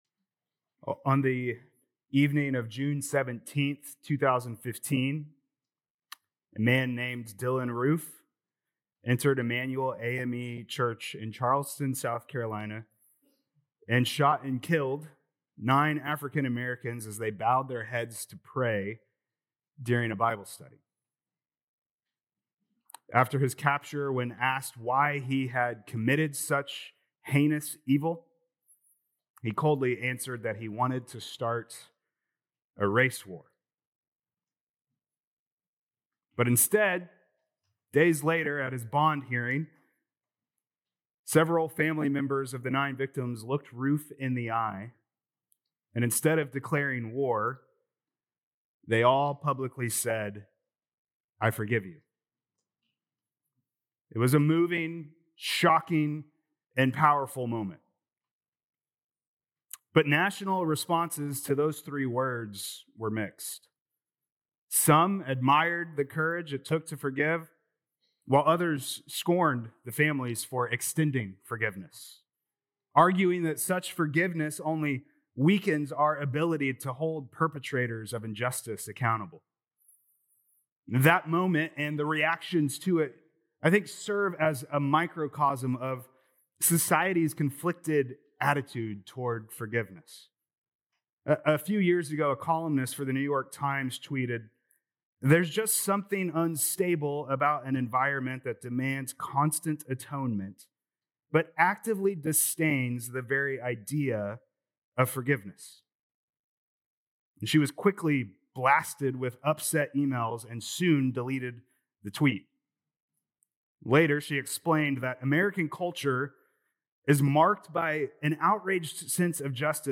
Jan 18th Sermon | Matthew 18:21-35